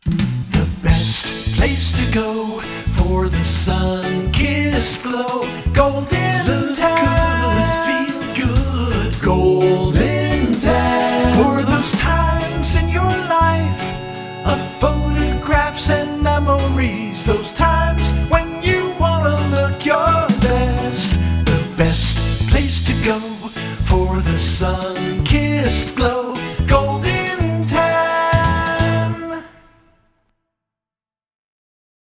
Radio Ad